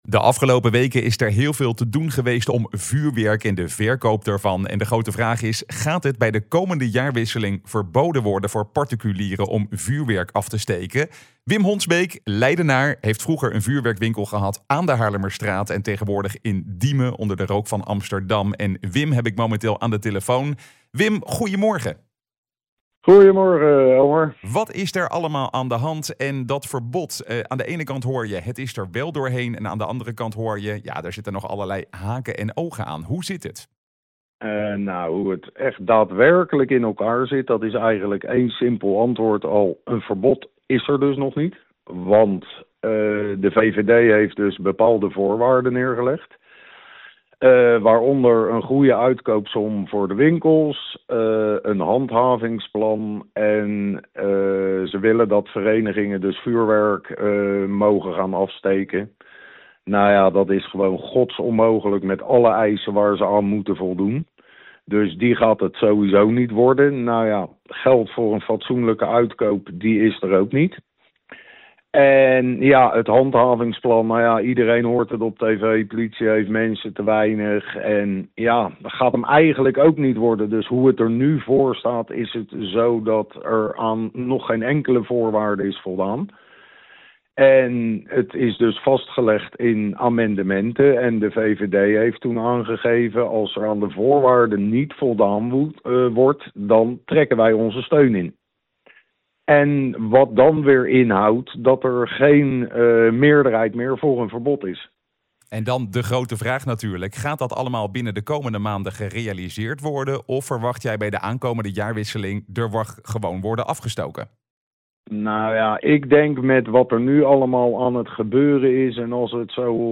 Presentator
in gesprek